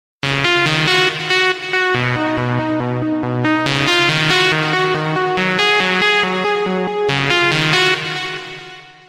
SMS Tone